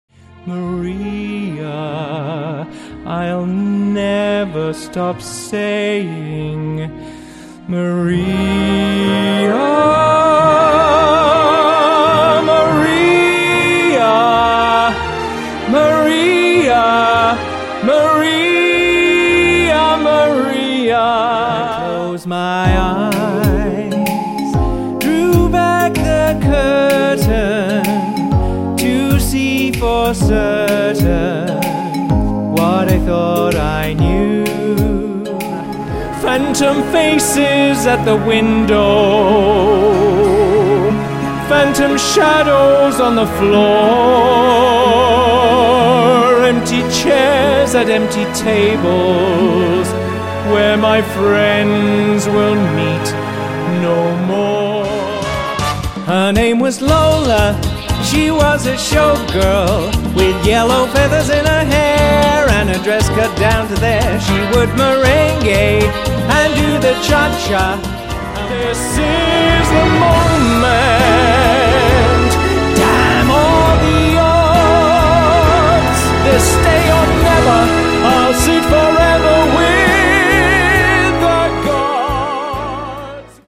West End singer
cabaret show